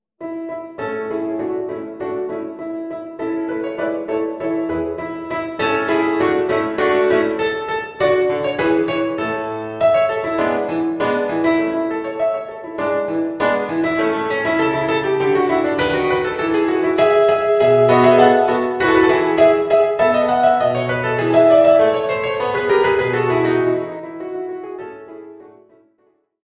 the result was a new series for piano